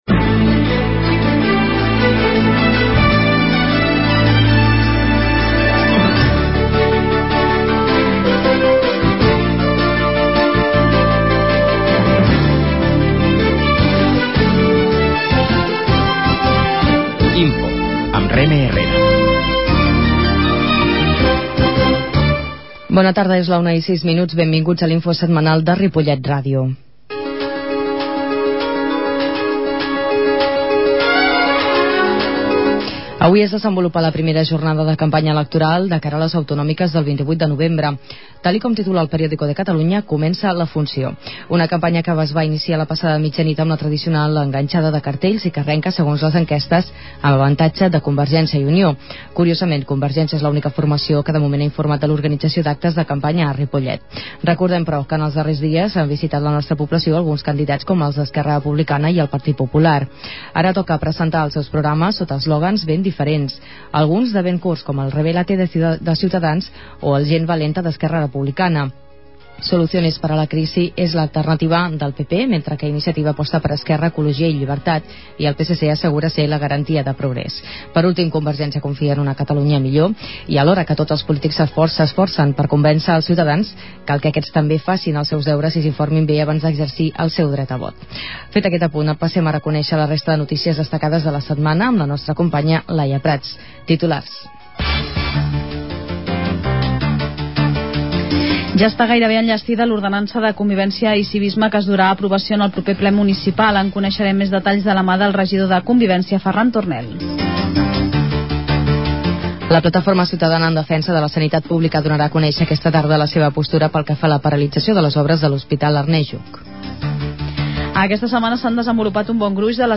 La qualitat de so ha estat redu�da per tal d'agilitzar la seva baixada.